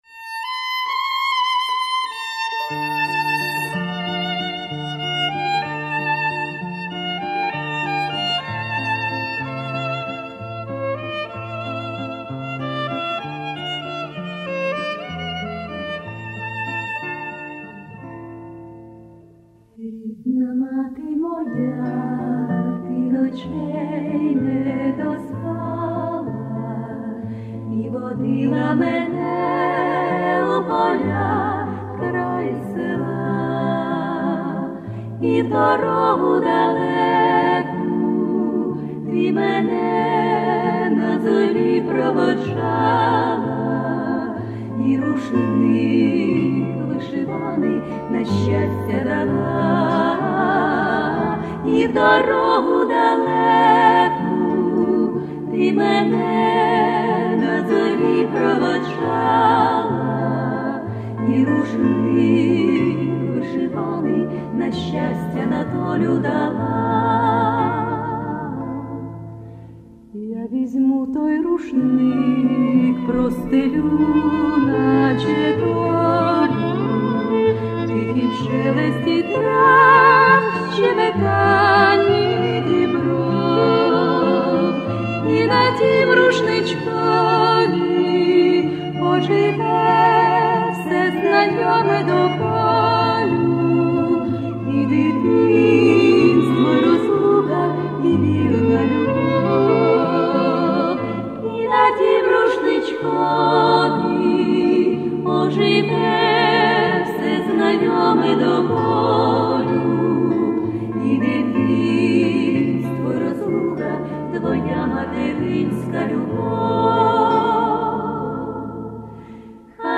ukrainskienarodnyepesni_rushnikmp3toninfo.mp3